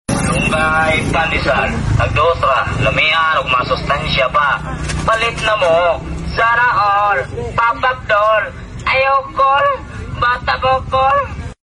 Malunggay Pandesal meme soundboard clip with playful, quirky tone and funny viral foodie vibe.